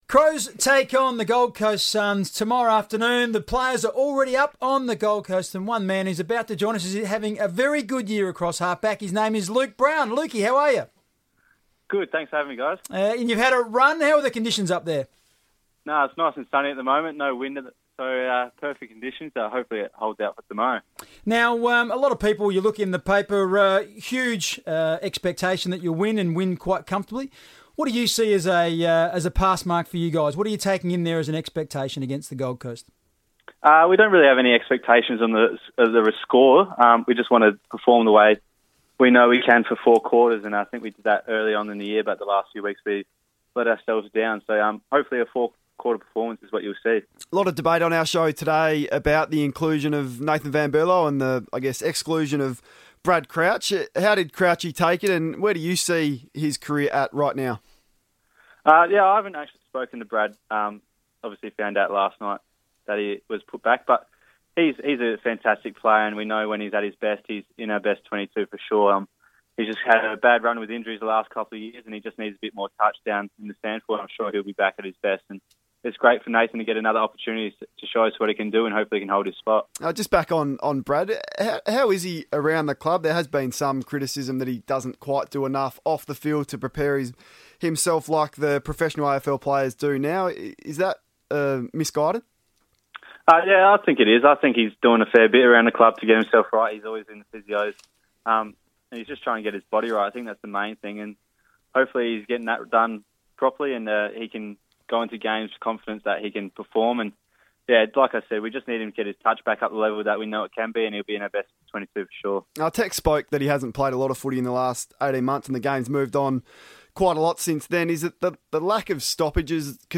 In-form defender Luke Brown spoke on FIVEaa radio ahead of Adelaide's clash with the Gold Coast Suns at Metricon Stadium.